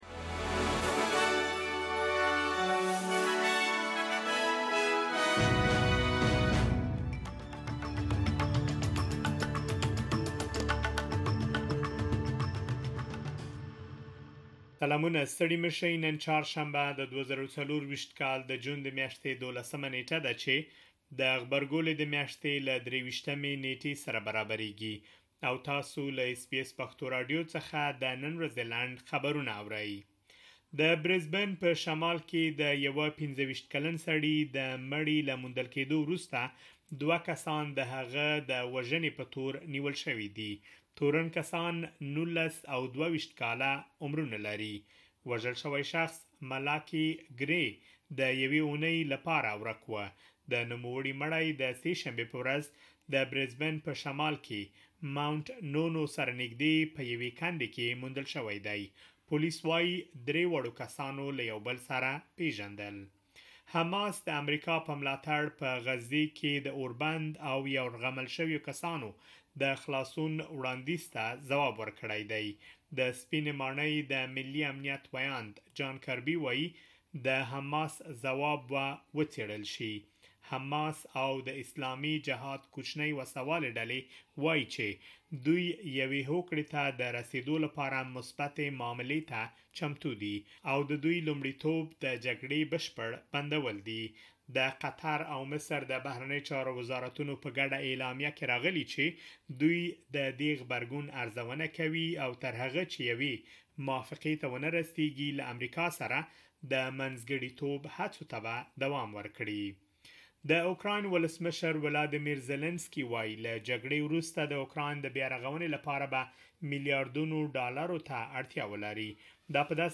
د اس بي اس پښتو د نن ورځې لنډ خبرونه|۱۲ جون ۲۰۲۴